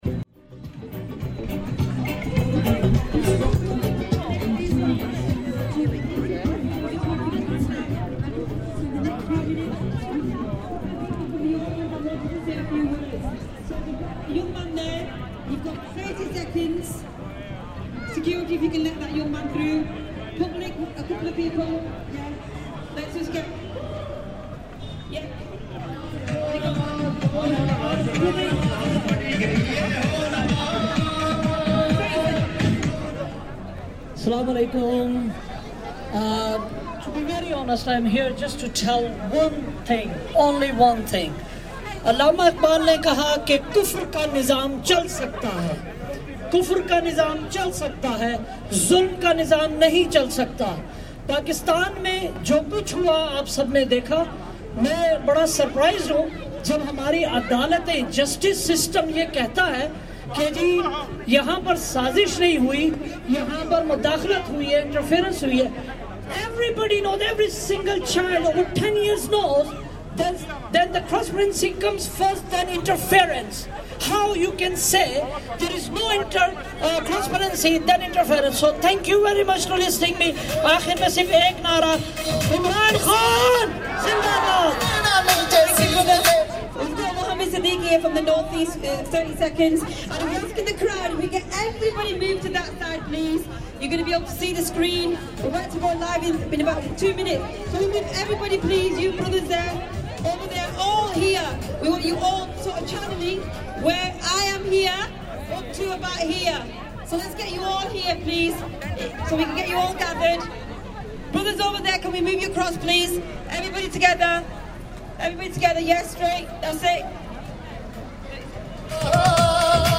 In Piccadilly Gardens, Manchester, two simultaneous organised protests are taking place very close to one another at the same time in the spring of 2022. The protests are about completely different things - one is a pro-Imran Khan rally by the Pakistani community, and the second a protest against the war in Ukraine, organised by the Ukrainian community.
In this recording, we move first from the Imran Khan rally and then on to the Ukrainian protest, so you can hear both, as well as the transition in the short walk between the two.